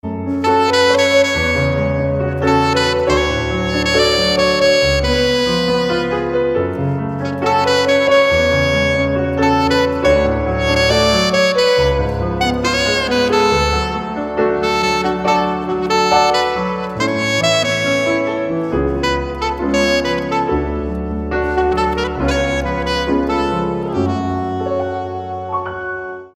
Saxophone & piano cover version